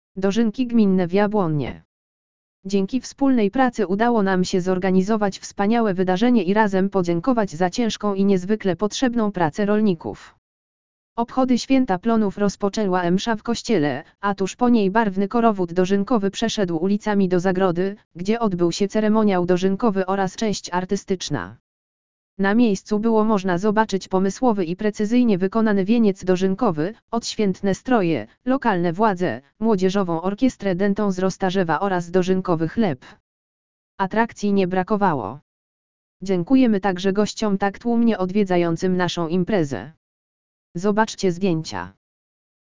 dozynki_gminne_w_jablonnie.mp3